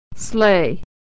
Lessons about authentic use and pronunciation of American English
Vowel Sounds /ɛ/-/ey/
e-ey-sleigh.mp3